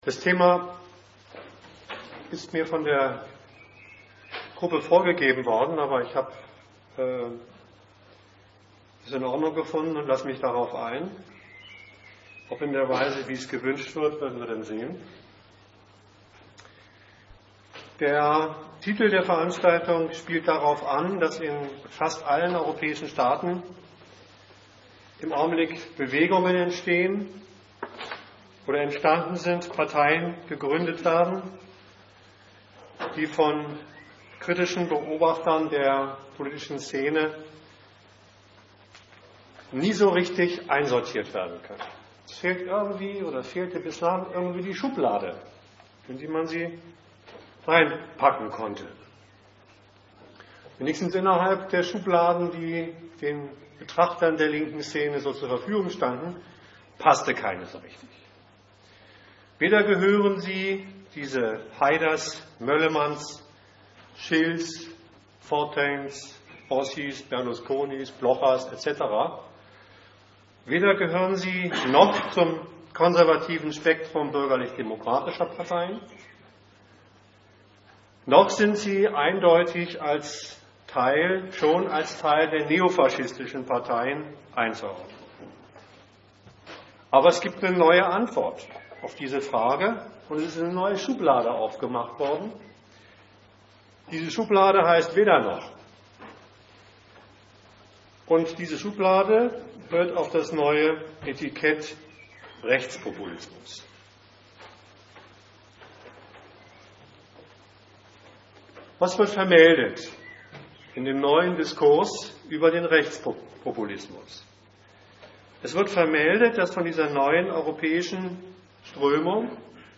Die Veranstaltung fand statt unter dem Titel: Demokratie, Rechtspopulismus, Neofaschismus Weitere Publikationen zum Thema von argudiss oder von anderen: Nachwort auf ein aussterbendes "Paradigma" Demokratie als Argument in der Weltpolitik in G...